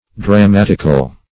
\Dra*mat"ic*al\ (dr[.a]*m[a^]t"[i^]*kal), a. [Gr. dramatiko`s,